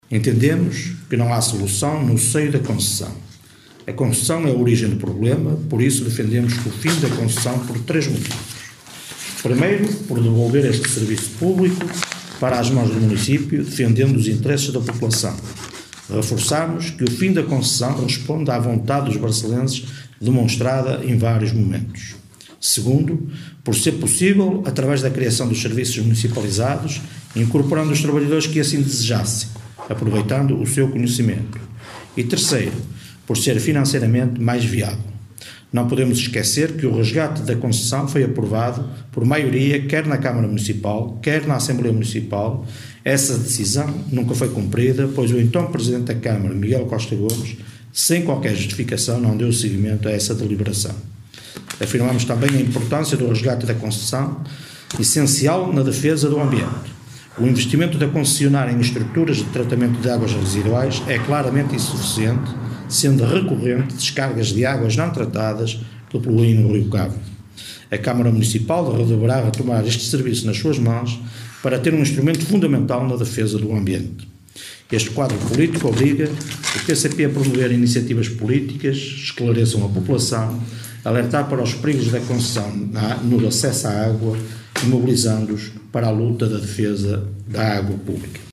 em conferência de imprensa, na tarde desta segunda-feira.